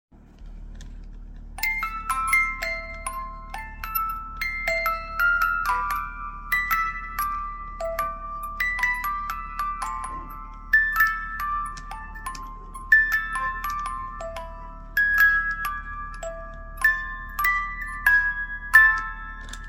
Cajita musical de manivela